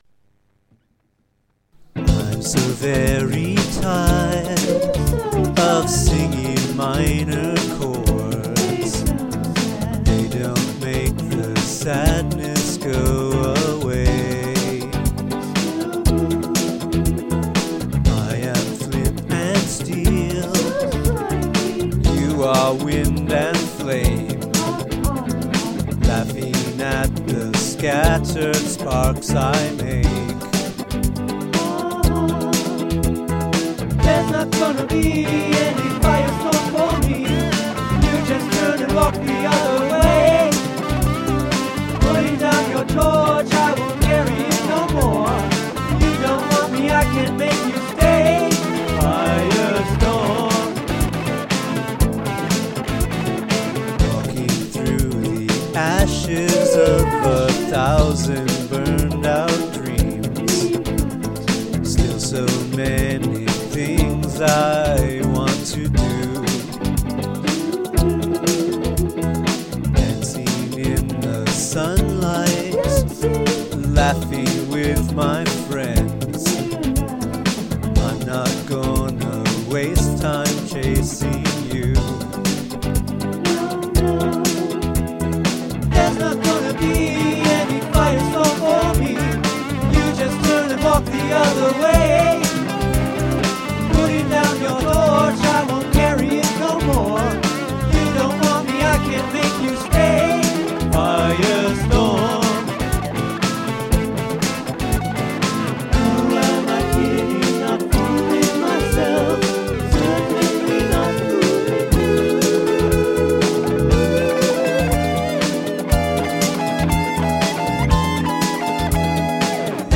Use of Falsetto